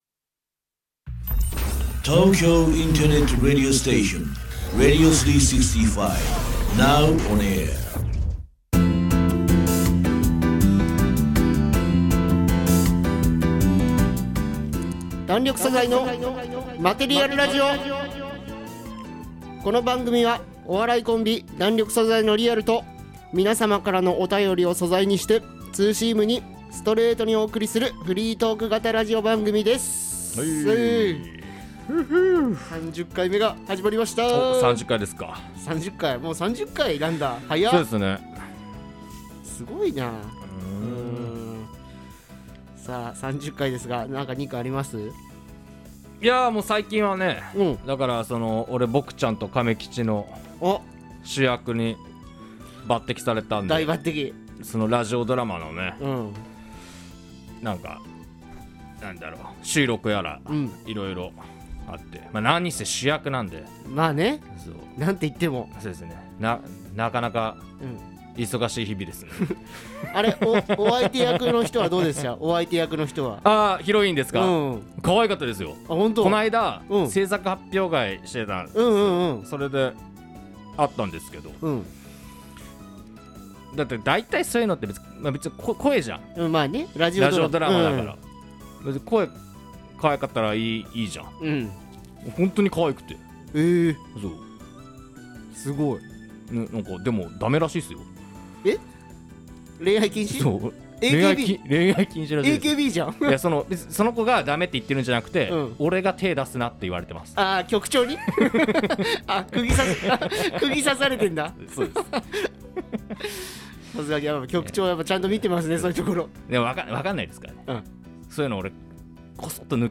お笑いコンビ弾力素材の30回目のラジオです！！テンション高めです！！